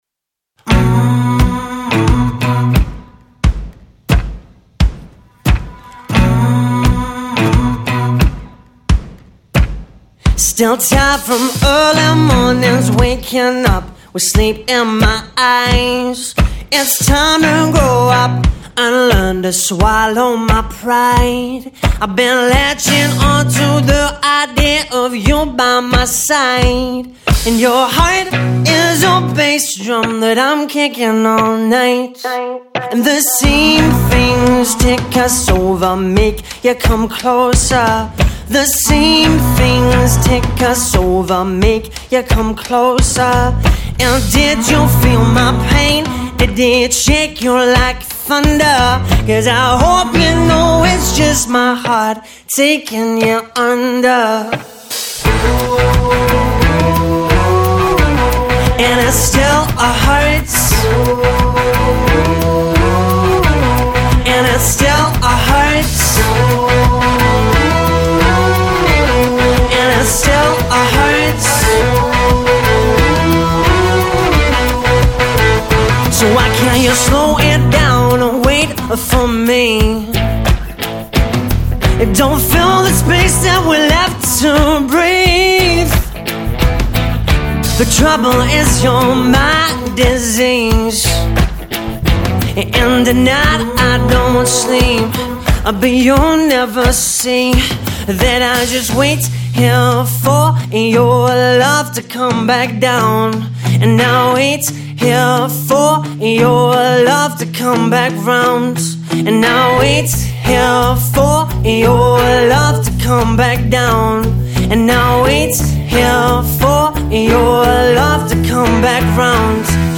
Acoustic singer-songwriter